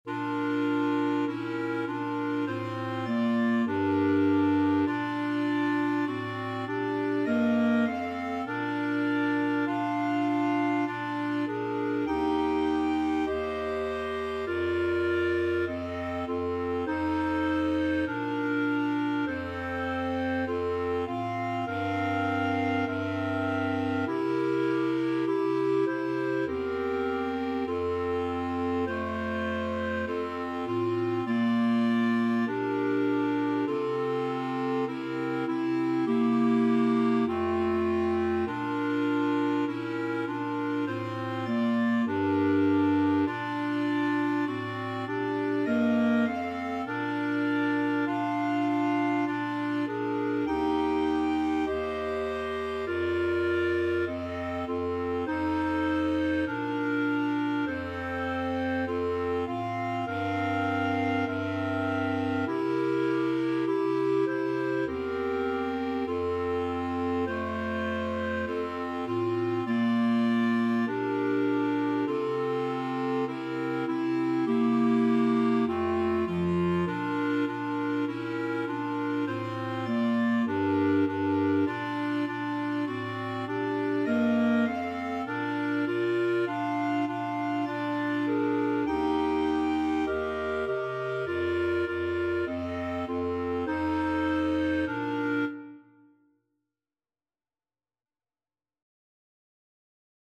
Clarinet Quartet version
Clarinet 1Clarinet 2Clarinet 3Clarinet 4/Bass Clarinet
4/4 (View more 4/4 Music)
Bb major (Sounding Pitch) C major (Clarinet in Bb) (View more Bb major Music for Clarinet Quartet )
Andante con moto (View more music marked Andante con moto)
Traditional (View more Traditional Clarinet Quartet Music)